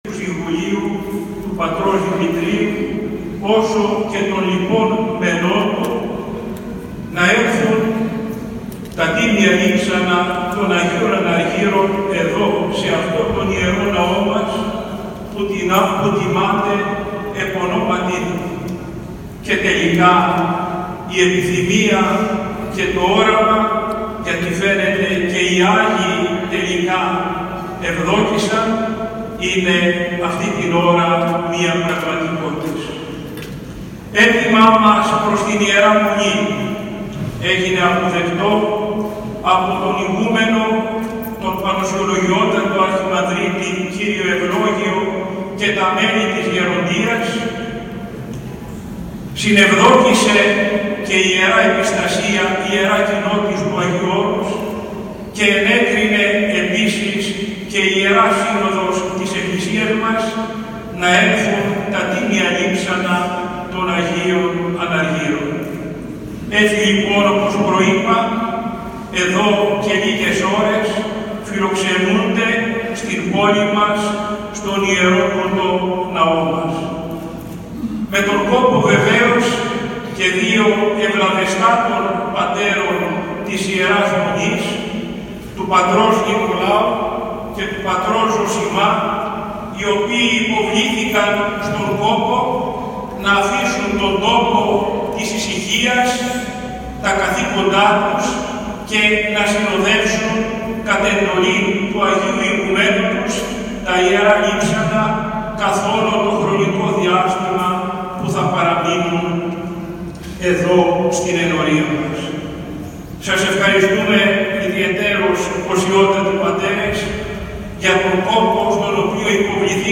Ακούστε το κήρυγμα του Σεβασμιωτάτου Μητροπολίτη Νέας Σμύρνης κ. Συμεών στο παρακάτω ηχητικό: